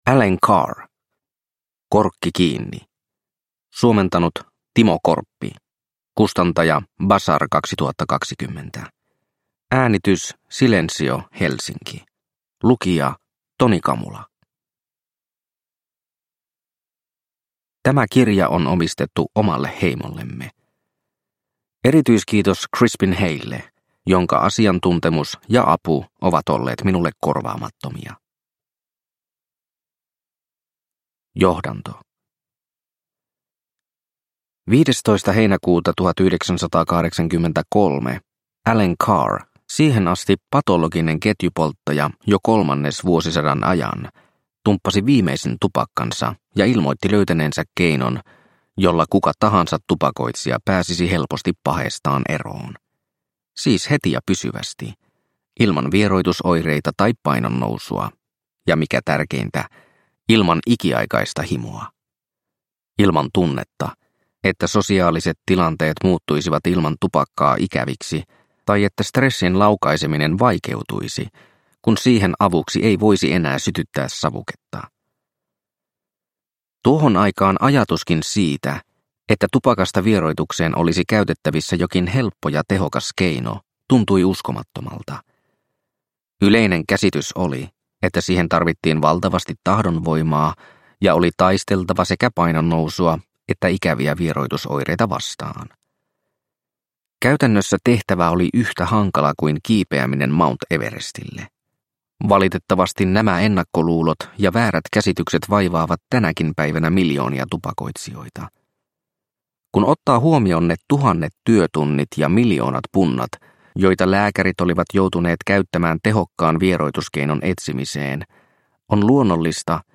Korkki kiinni! – Ljudbok – Laddas ner